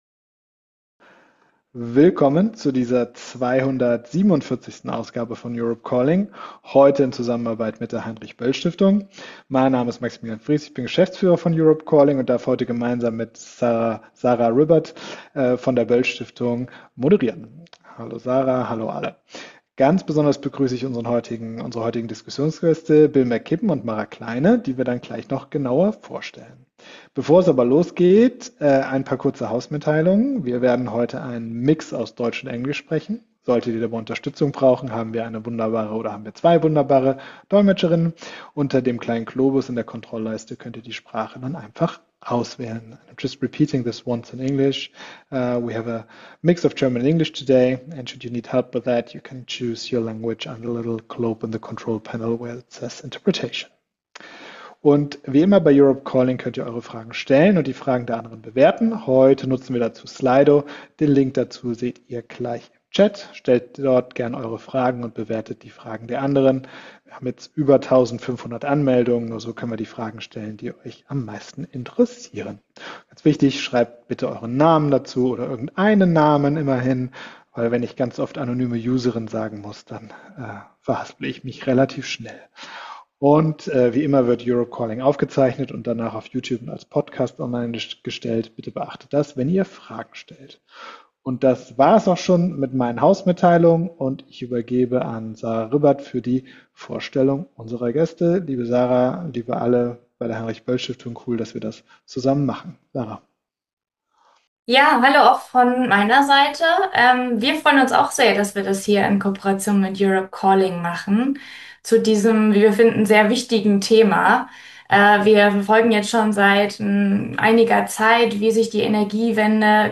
Beschreibung vor 4 Monaten Aufzeichnung der 247. Ausgabe von Europe Calling. Am 1.12.2025 in Zusammenarbeit mit der Heinrich-Böll-Stiftung und folgenden Gästen: - Bill McKibben ist US-amerikanischer Umweltschützer, Autor und Journalist; Mitbegründer von 350.org und Mitbegründer von Third Act.